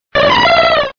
Fichier:Cri 0189 DP.ogg